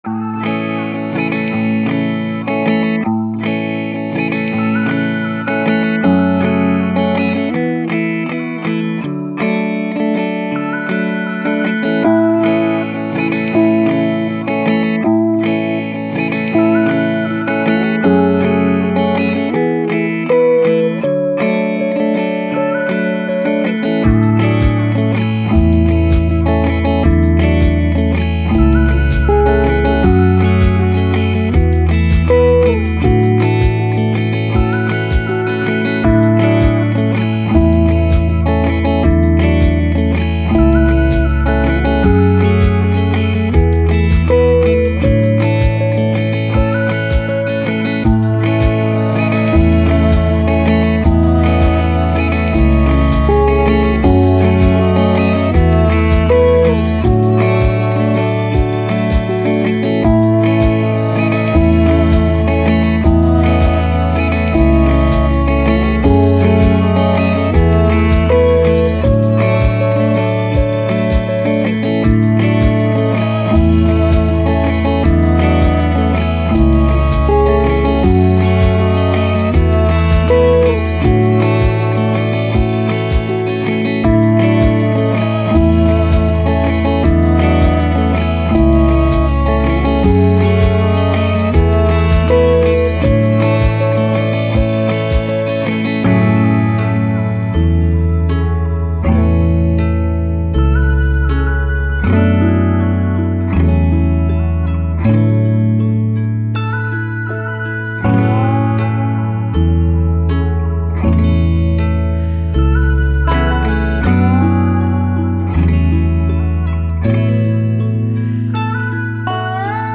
Downtempo, Guitars, Soundtrack, Emotive, Thoughtful